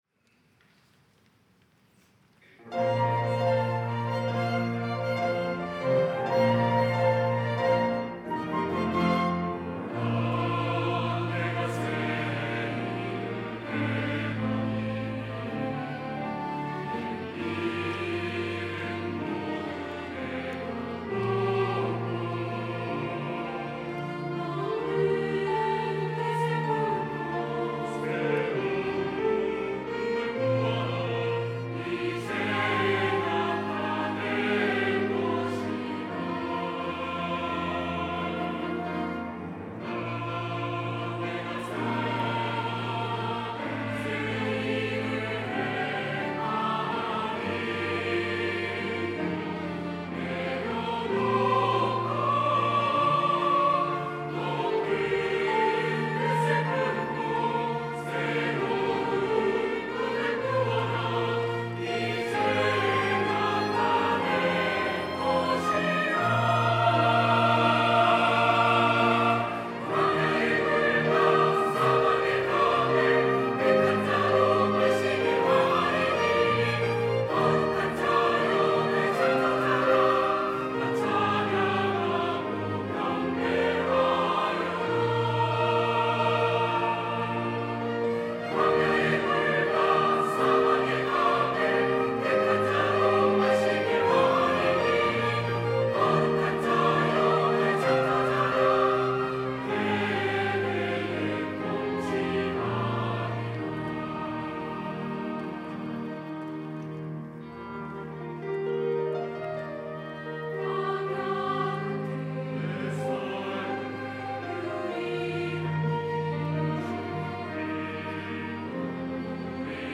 호산나(주일3부) - 주를 보라
찬양대